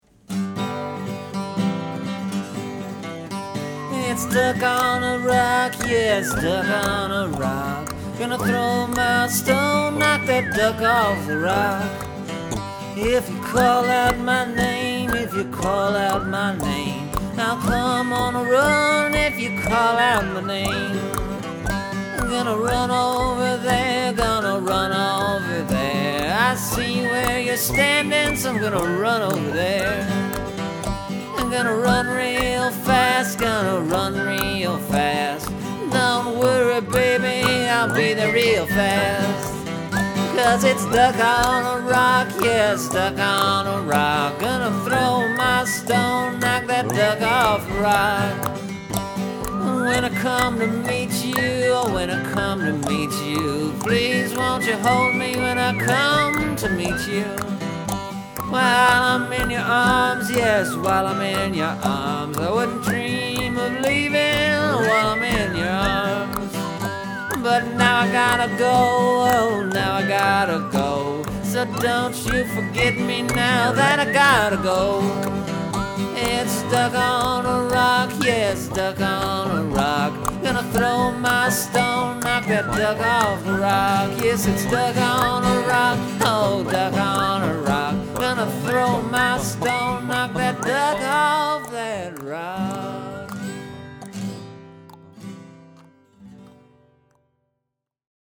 And then I added some background noise.